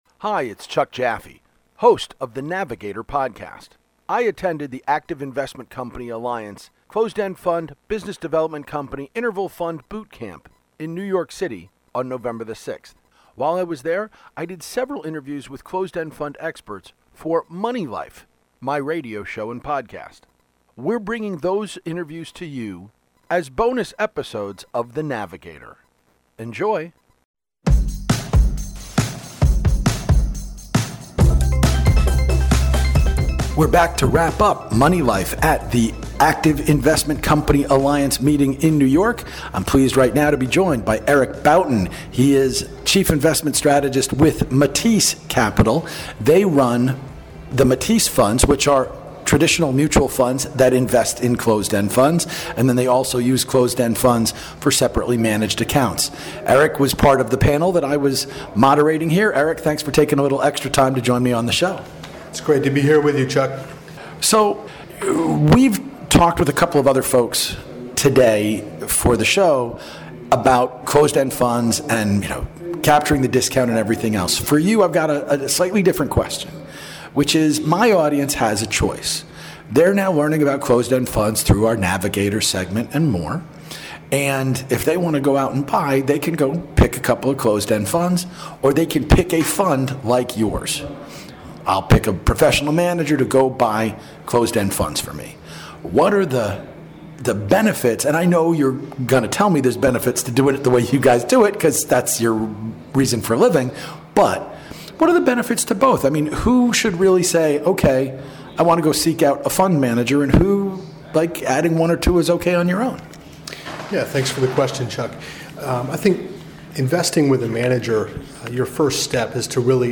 In this bonus edition of The NAVigator taped at the Active Investment Company Alliance Boot Camp and Roundtable in New York City on Nov. 6